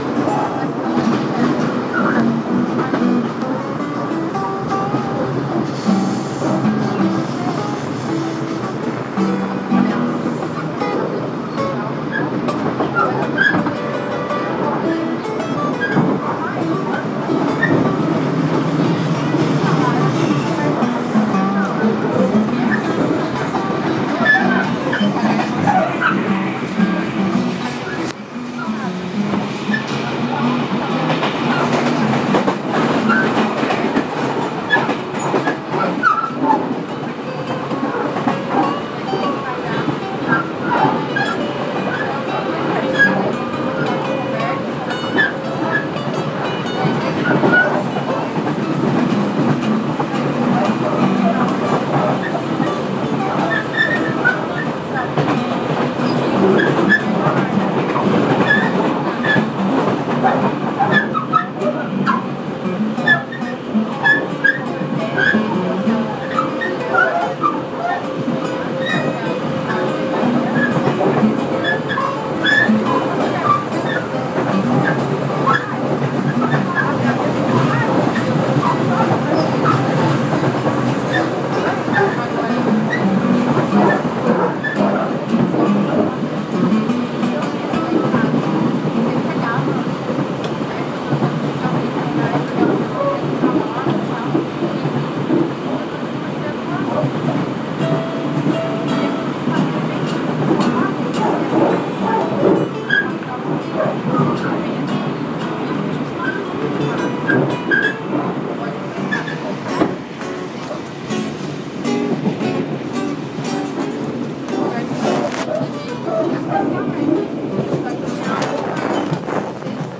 Blues no Trensurb
Músico sentou a meu lado entre as estações Sapucaia e Unisinos.